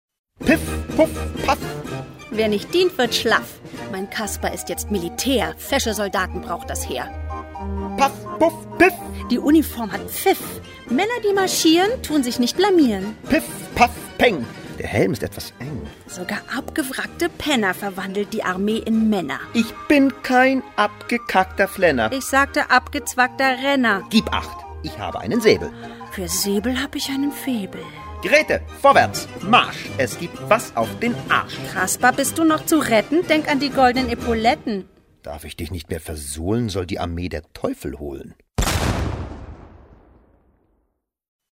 Minihörspiele, die für abwegige Gedanken, neue Wahrnehmungen und intelligenten Humor werben.